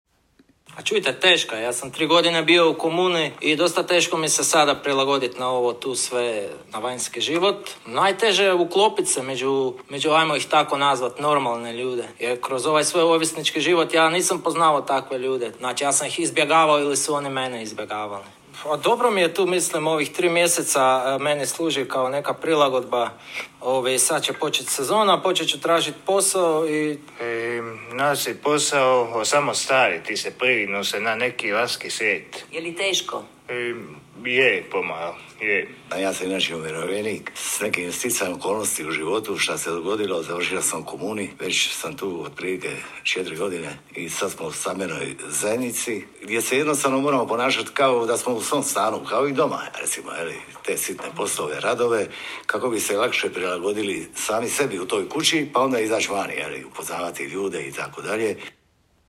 Reportaža o životu korisnika u organiziranom stanovanju Doma za ovisnike ZAJEDNICA SUSRET u Kaštel Novom emitirana je na Radio Splitu u srijedu, 09.03.2022. godine
Emisija na Radio Splitu emitirana je u sklopu Kampanje s ciljem podizanja svijesti javnosti o potrebama deinstitucionalizacije osoba s problemom ovisnosti .